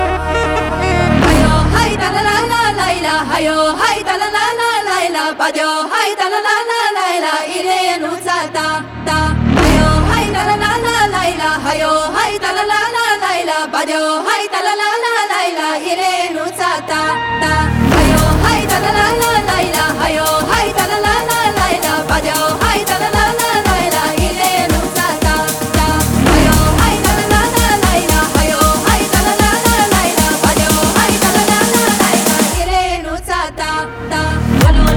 Жанр: Танцевальные / Хаус
House, Dance